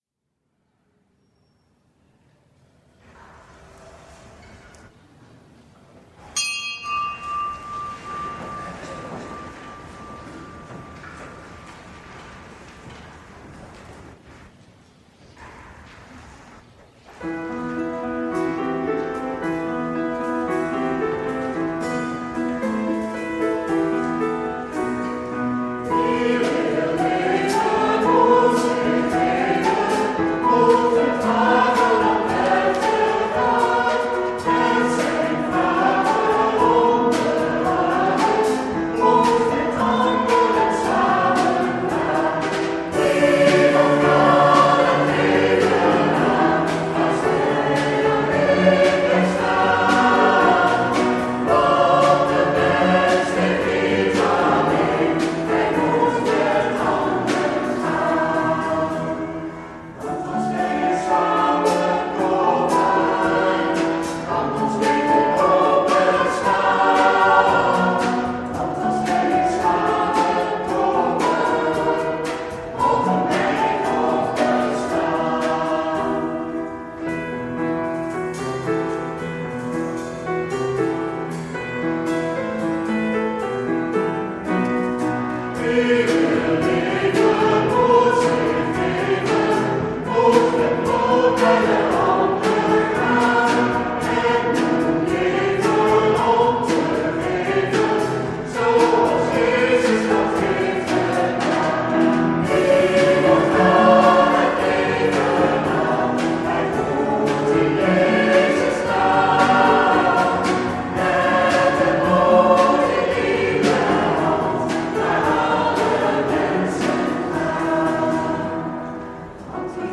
Lezingen
Eucharistieviering beluisteren vanuit de Sint Jozef te Wassenaar (MP3)